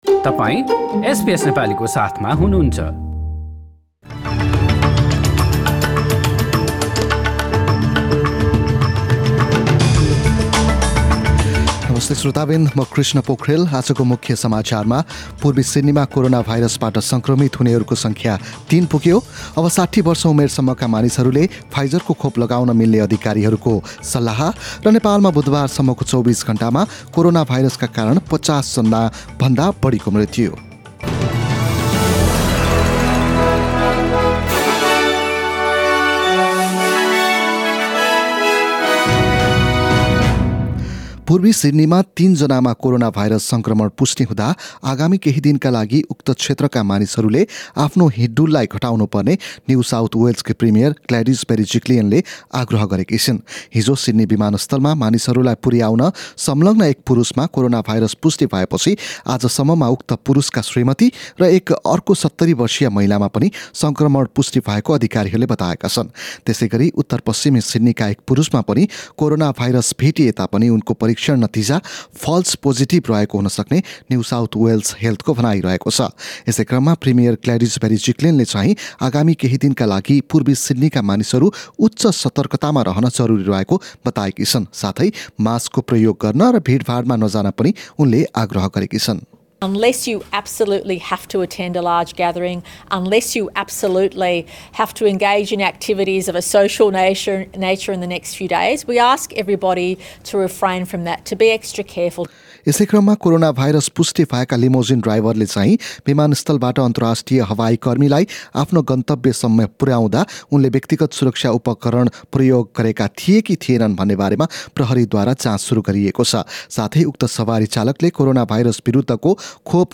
एसबीएस नेपाली अस्ट्रेलिया समाचार: बिहिबार १७ जुन २०२१